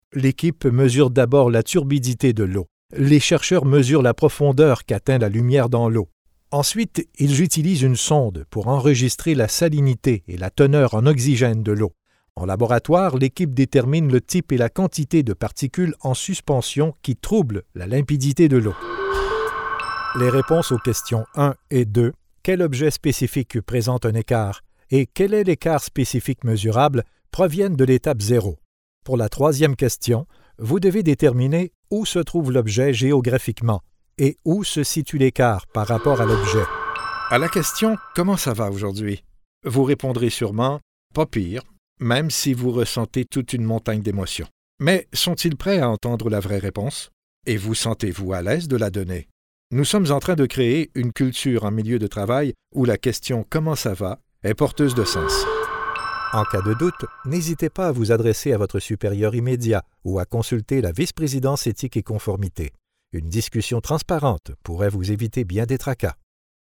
Voiceover Male